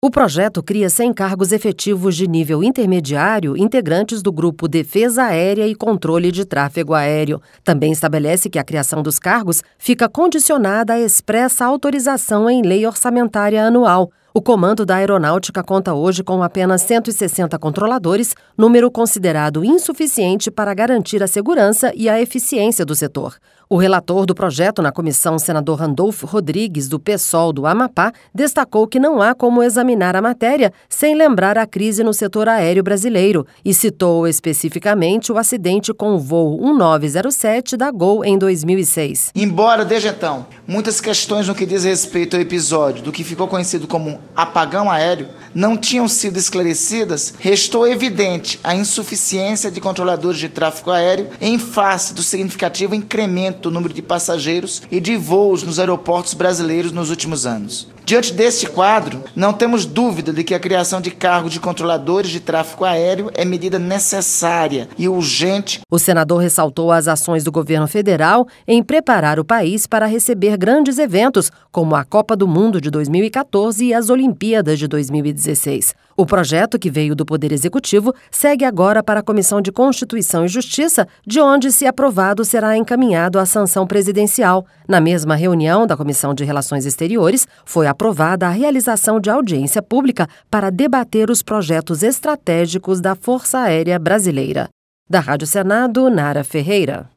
O relator do projeto na Comissão, senador Randolfe Rodrigues, do PSOL do Amapá, destacou que não há como examinar a matéria sem lembrar a crise no setor aéreo brasileiro.